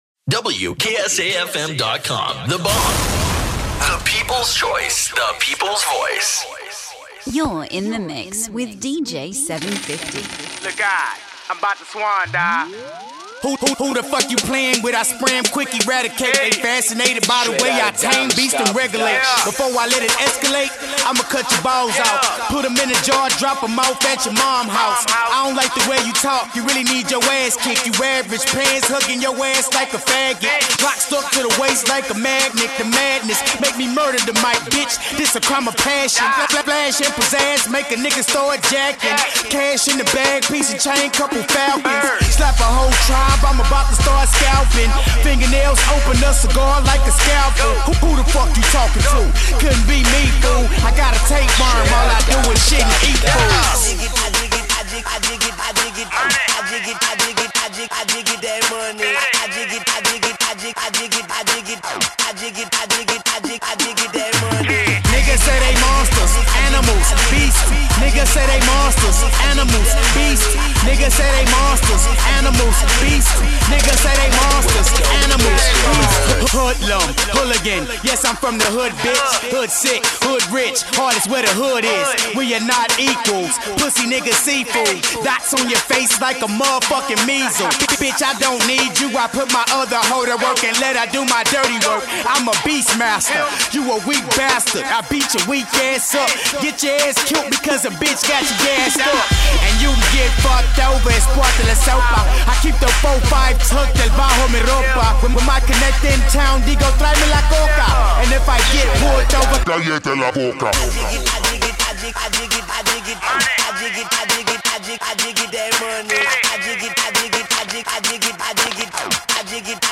DJ Mixes
HIP HOP